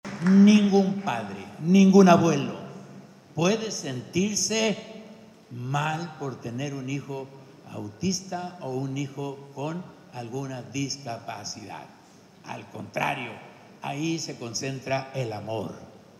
“Ningún padre, ningún abuelo, puede sentirse mal por tener un hijo autista o un hijo con alguna discapacidad, al contrario ahí se concentra el amor”, afirmó Rocha Moya.
CITA-2-AUDIO-GOBERNADOR-RRM-PRESENTACION-DEL-LIBRO-AUTISMO-DE-LA-BRUMA-A-LA-ESPERANZA-.mp3